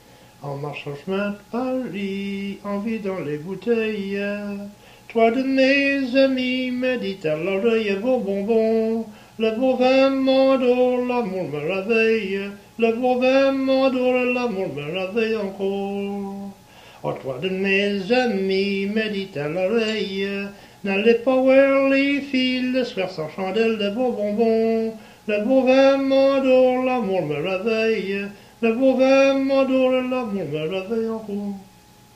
Chanson Item Type Metadata
Emplacement Cap St-Georges